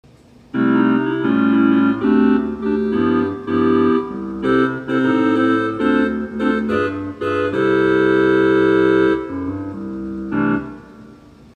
The stop imitates the orchestral clarinet extremely well.
The sound clip has the Clarinet un-tremmed.
Clarinet.mp3